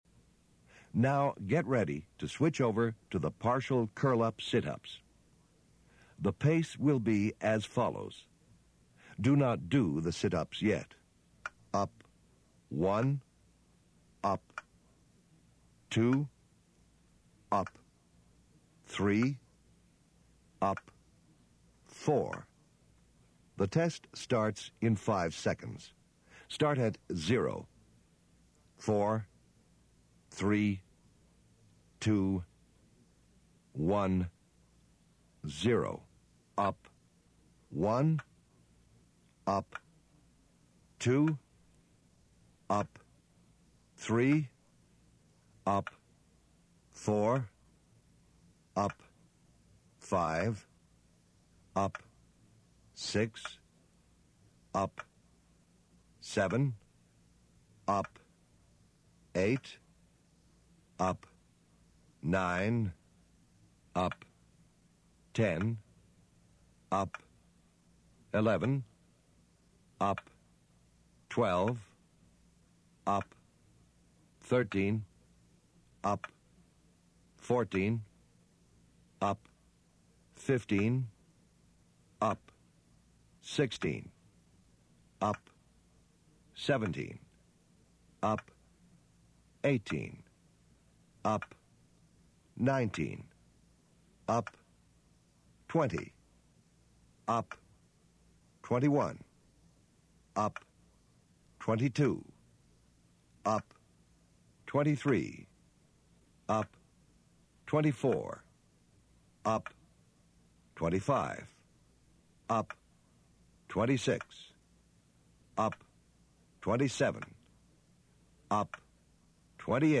04-Track-14-Push-up-and-Curl-up-Cadence-1.mp3